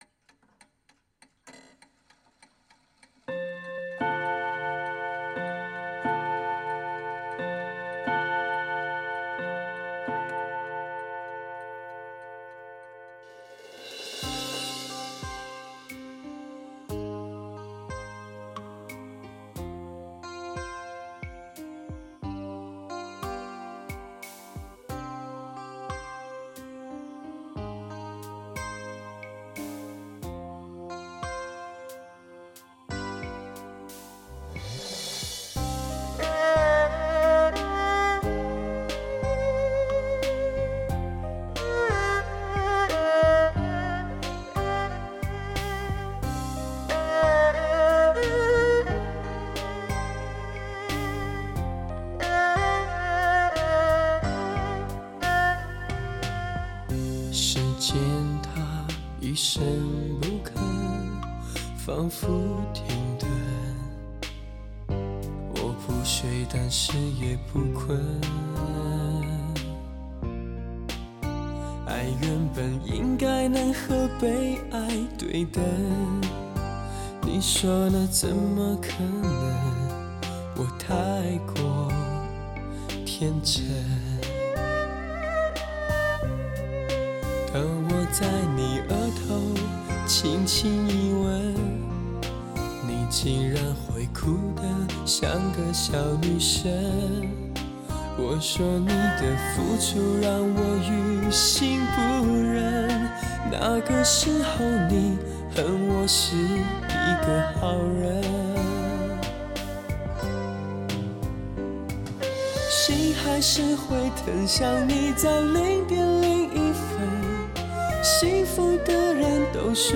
6.1DTS-ES CD 典范 神奇高保真质感
环绕立体声音响 至高享受
带来超乎想像震憾性的环绕声体验。